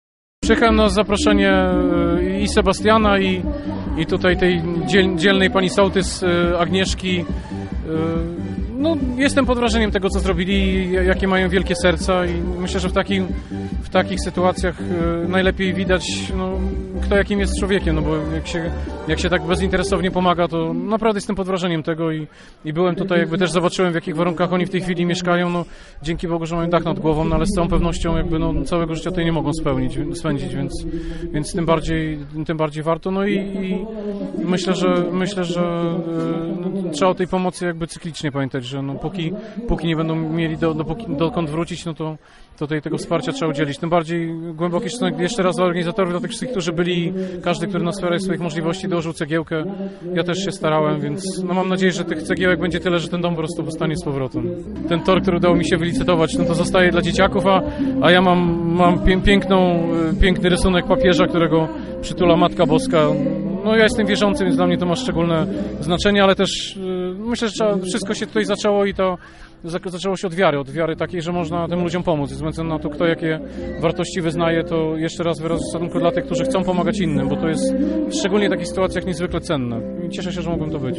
Mówił poseł Piotr Król, uczestniczący we wczorajszym koncercie.